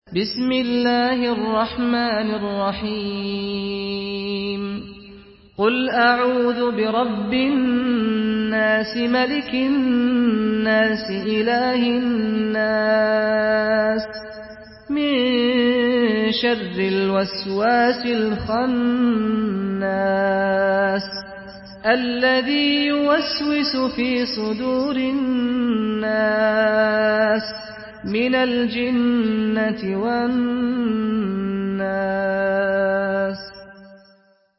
Surah আন-নাস MP3 in the Voice of Saad Al-Ghamdi in Hafs Narration
Surah আন-নাস MP3 by Saad Al-Ghamdi in Hafs An Asim narration.
Murattal Hafs An Asim